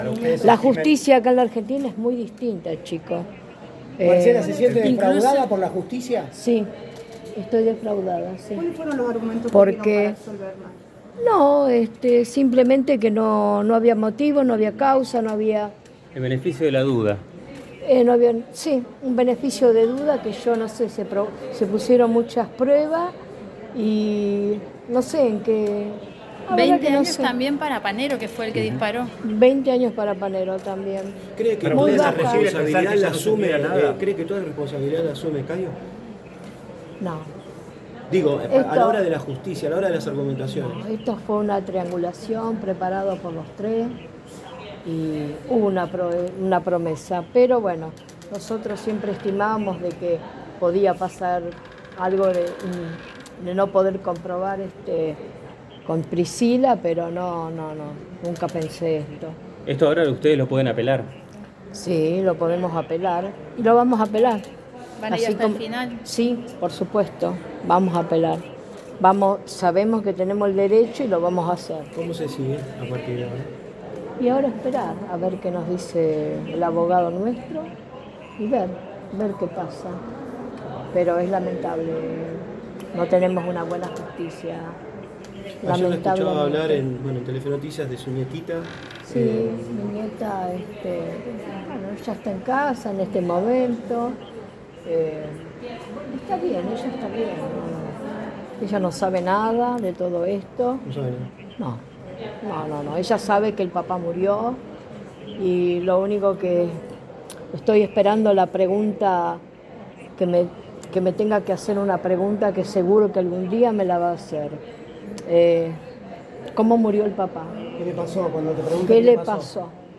"Me siento defraudada" dijo en el móvil de Cadena 3 Rosario.